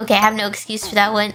Worms speechbanks
Coward.wav